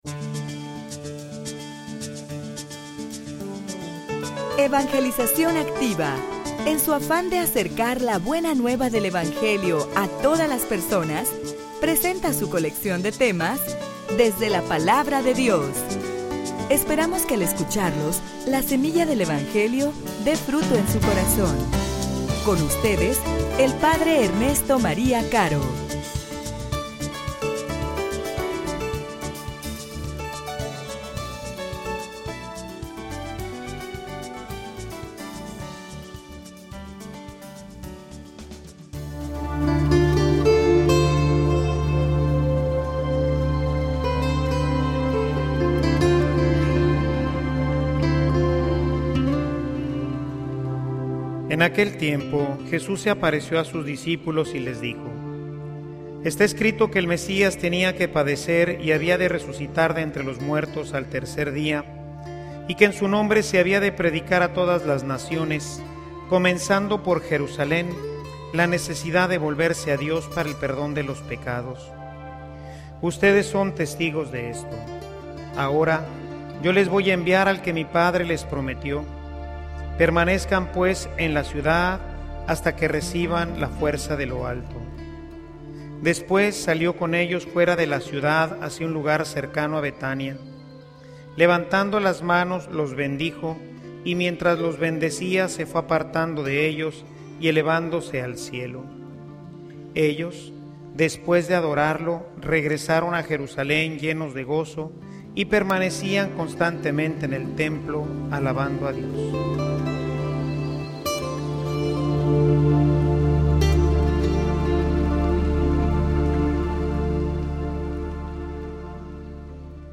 homilia_Jesus_cuenta_contigo.mp3